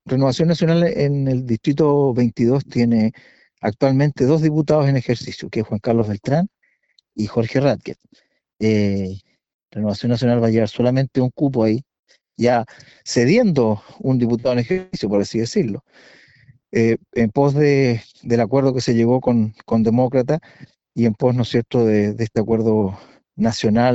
El presidente de RN en La Araucanía, Jorge Rathgeb, dijo que la tienda cedió un cupo debido al pacto que mantienen con Demócratas.